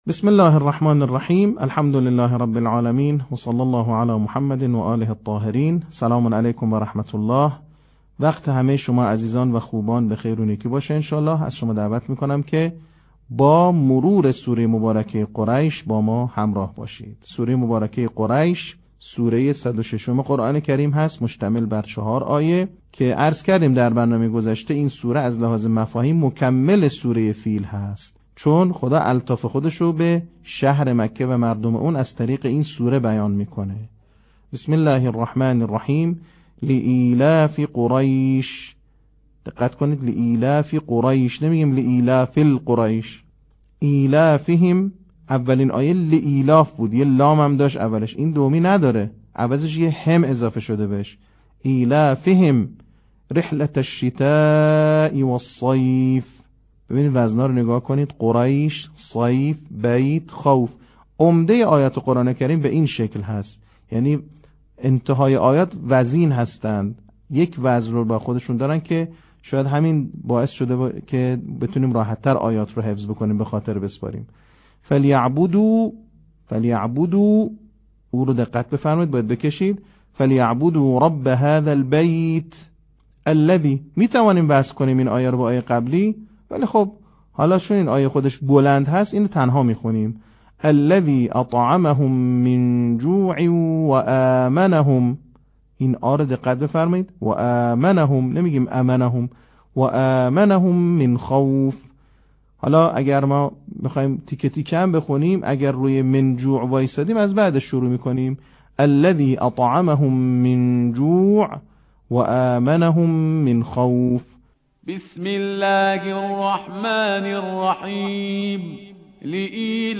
صوت | نکات آموزشی حفظ سوره قریش